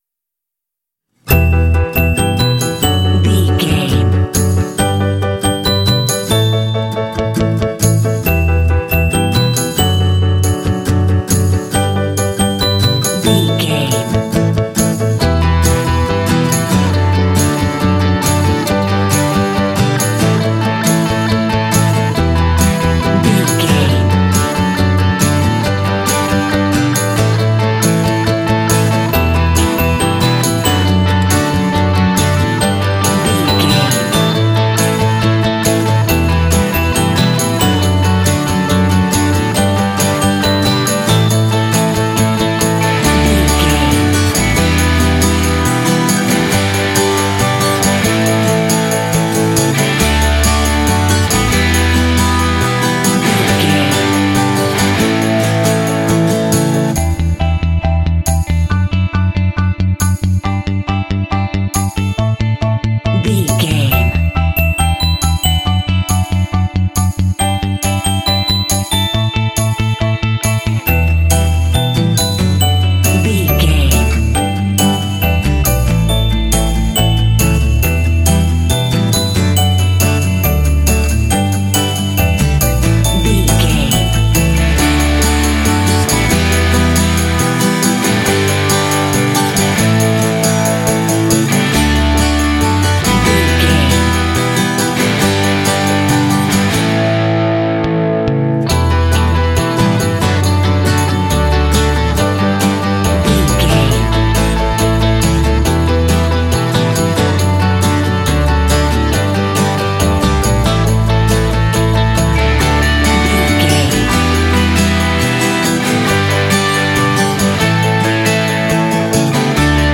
Fun and cheerful indie track with bells.
Uplifting
Ionian/Major
D
optimistic
bright
piano
bass guitar
percussion
electric guitar
pop
symphonic rock